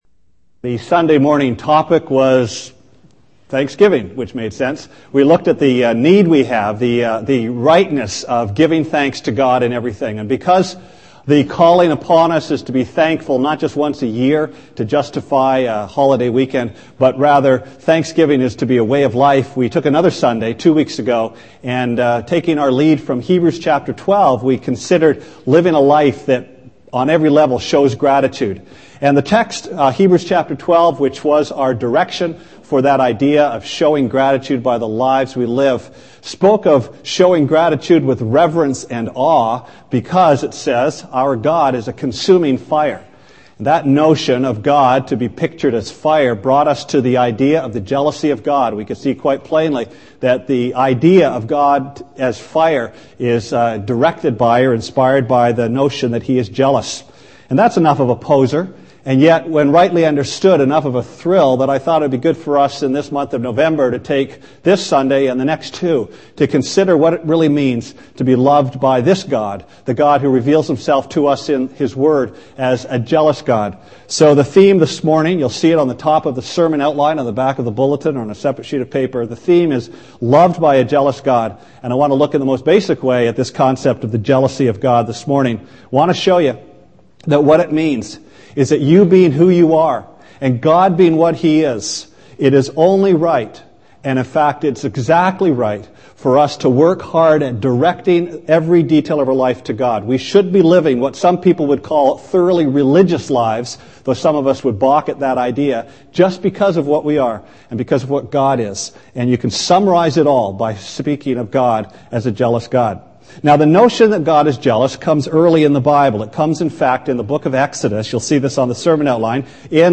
Sermon Archives Nov 2, 2003 - Good and Jealous?